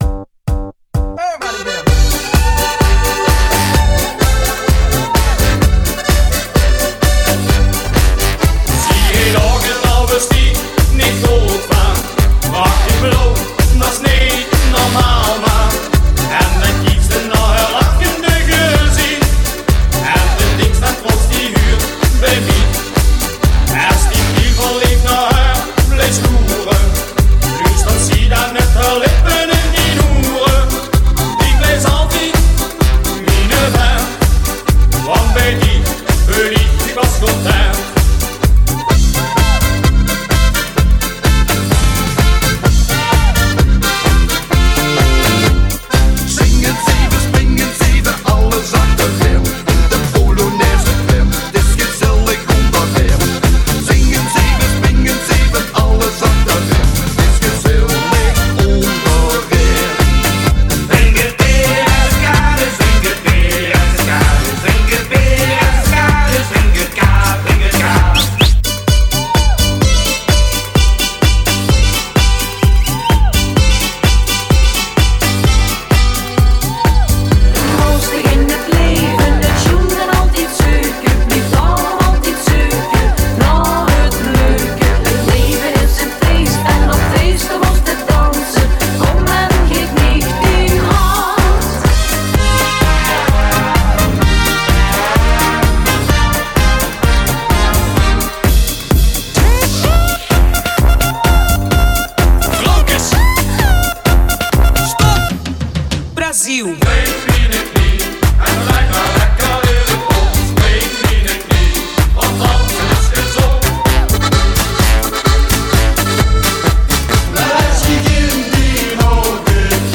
NOVEMBER 2014 • CARNAVAL • 4 MIN